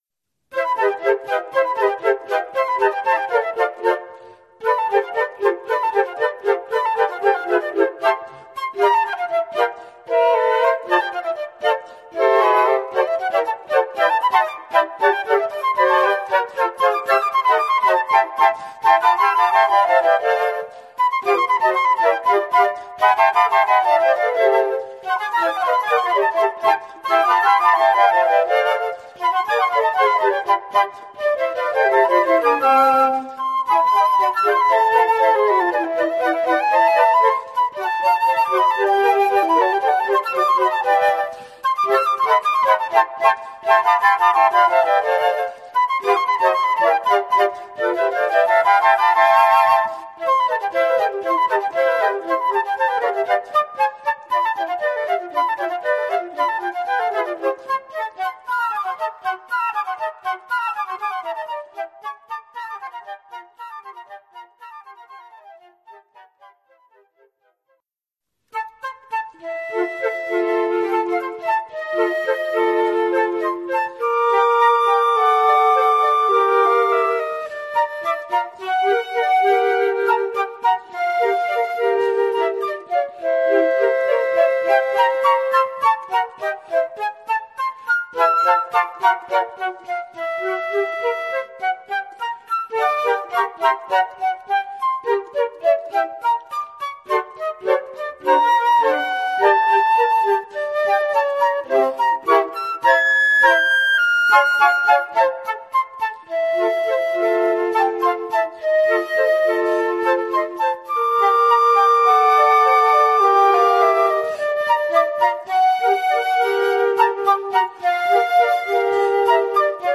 Oeuvre pour quatuor de flûtes.
Genre musical : Classique Formule instrumentale : Flûtes